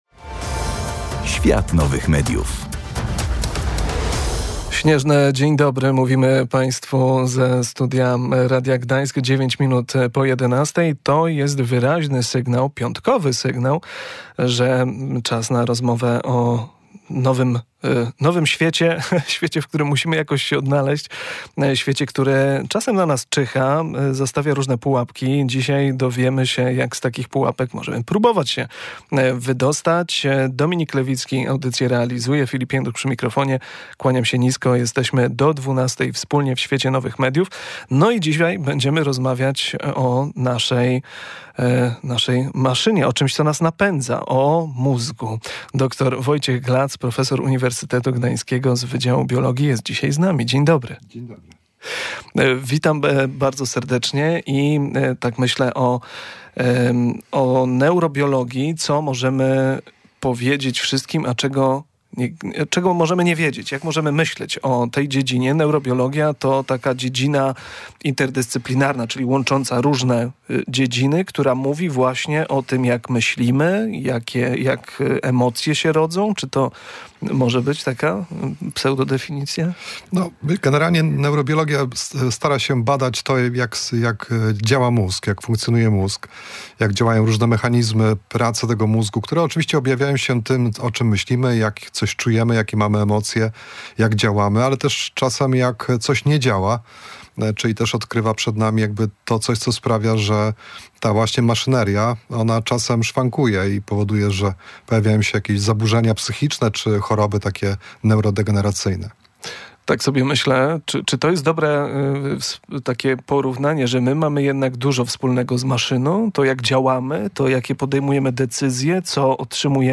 W kolejnym wydaniu "Świata Nowych Mediów" rozmawialiśmy o naszej osobistej skomplikowanej maszynerii, czyli mózgu.